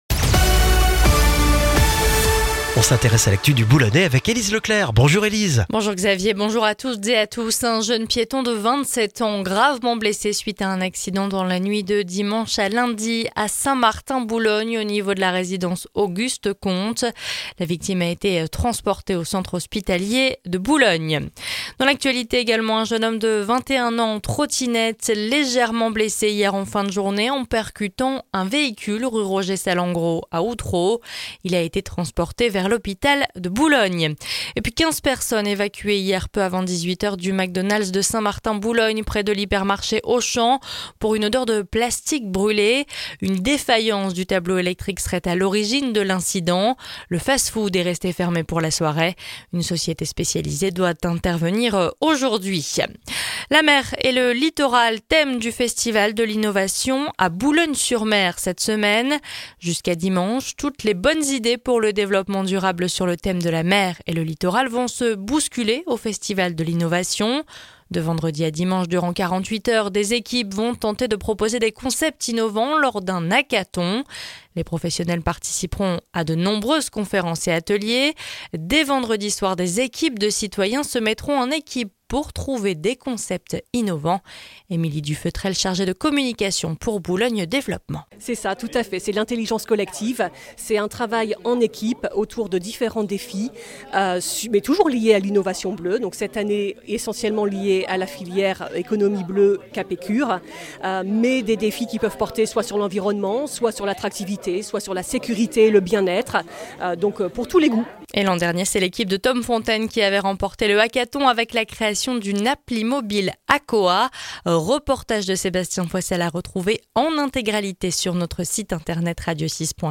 Le journal du mardi 12 novembre dans le Boulonnais